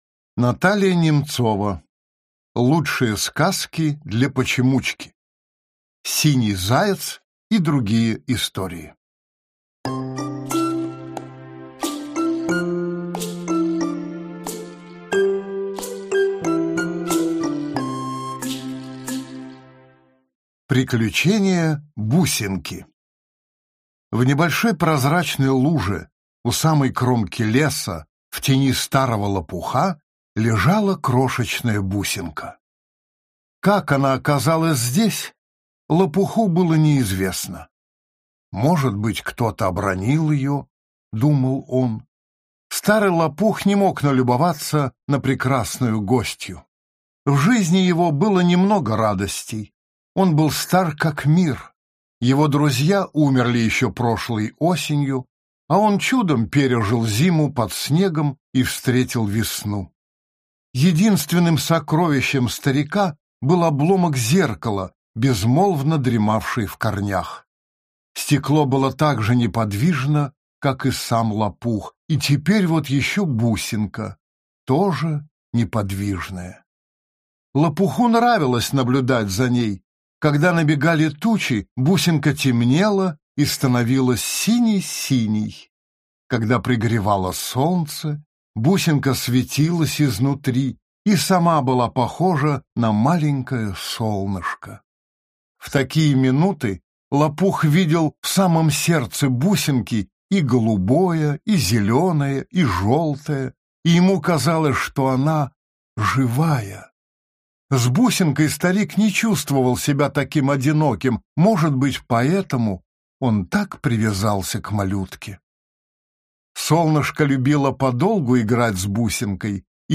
Аудиокнига Лучшие сказки для почемучки | Библиотека аудиокниг